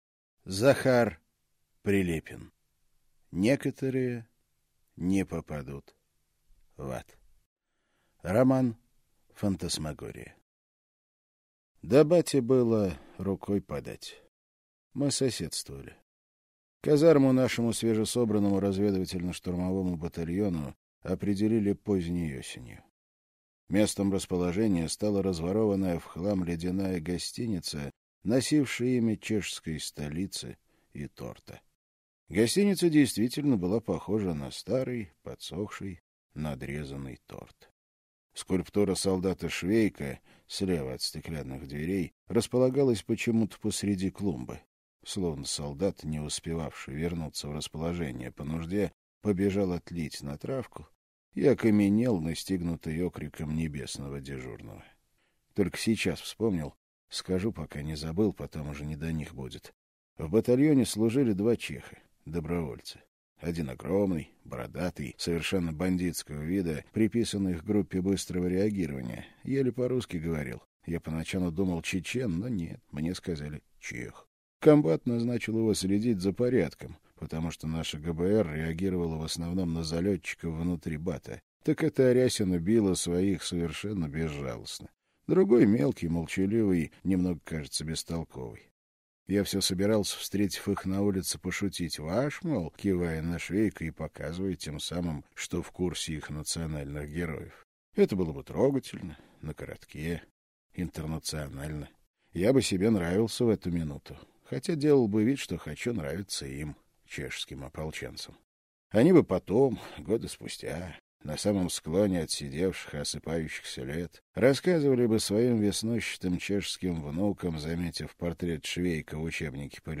Аудиокнига Некоторые не попадут в ад - купить, скачать и слушать онлайн | КнигоПоиск